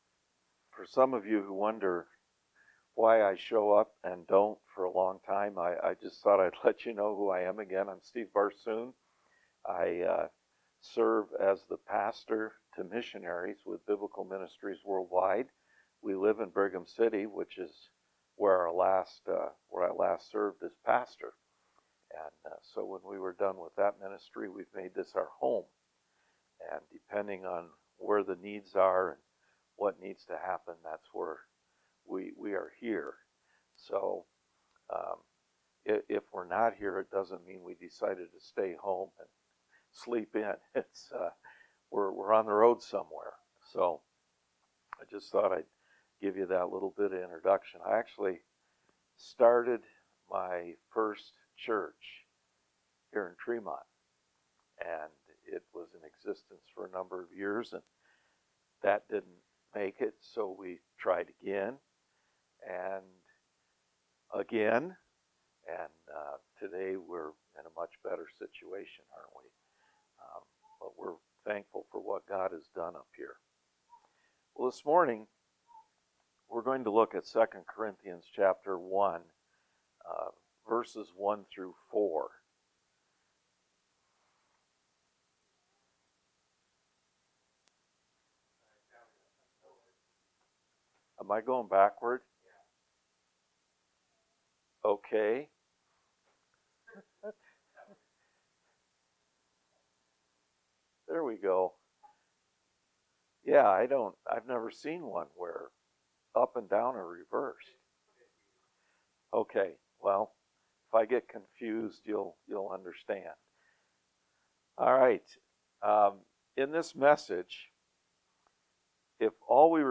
1 Corinthians 1 Service Type: Sunday Morning Worship « Romans 10:5-13 Romans 10:14-21